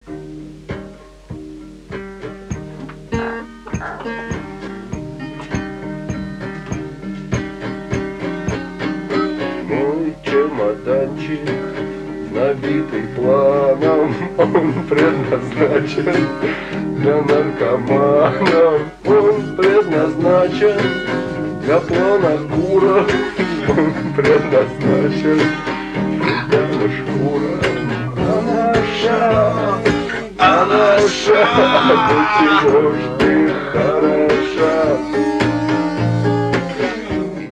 запоминающимся гитарным рифом